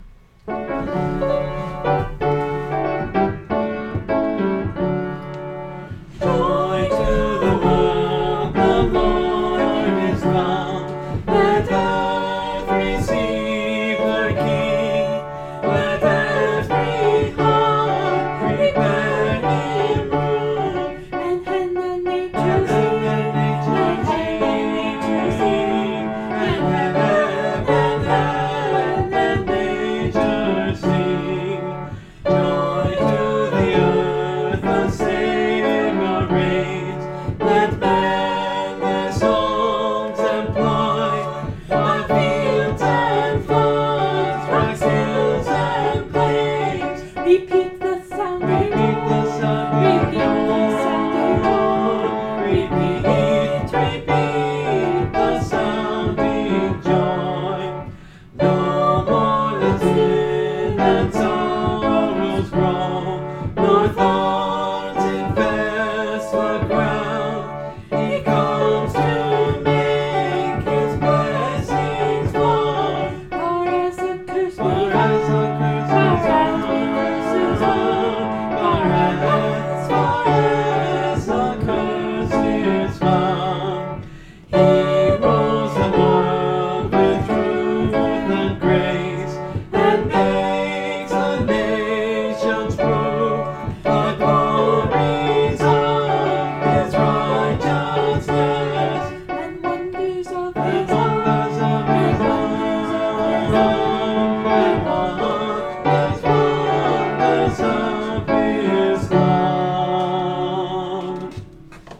(Part of a series singing through the hymnbook I grew up with: Great Hymns of the Faith)
This is a favorite Christmas Hymn, but it is really a hymn of the second, triumphal coming, not the virgin birth.